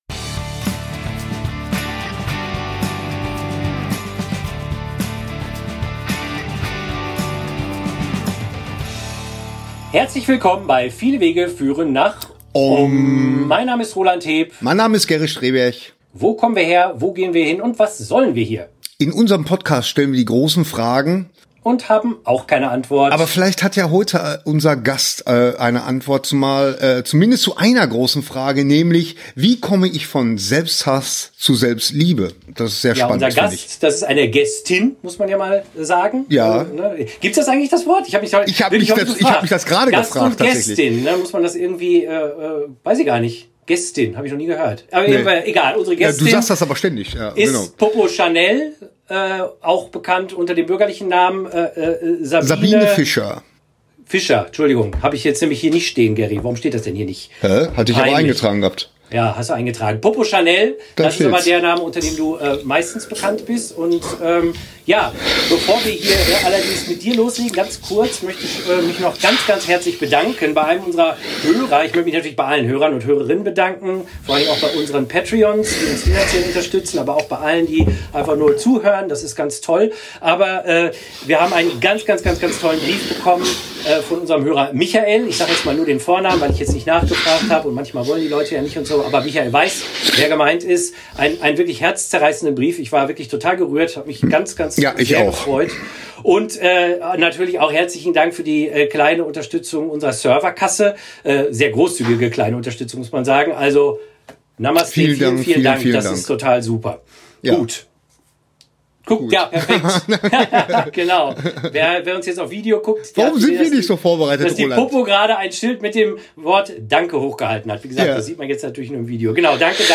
Beschreibung vor 5 Jahren Hinweis: aus technischen Gründen ist der Ton unseres Gastes in den ersten 14 Minuten dieser Episode etwas blechern.